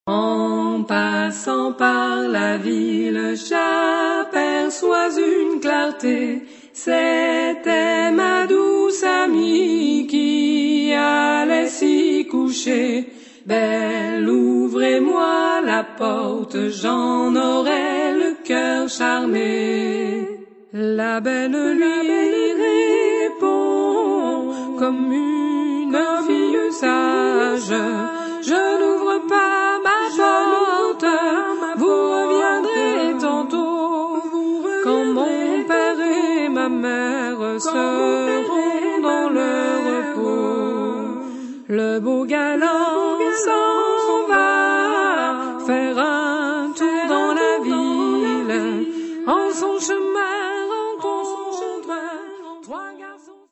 dans les arrangements polyphoniques à capella.